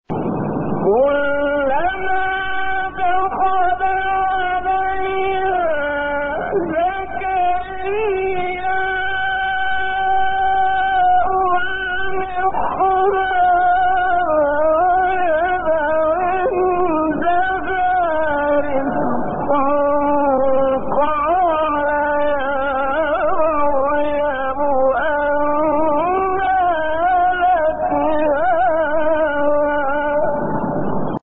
گروه شبکه اجتماعی: فرازهای صوتی از سوره آل‌عمران با صوت کامل یوسف البهتیمی که در مقام‌های مختلف اجرا شده است، می‌شنوید.
مقام حجاز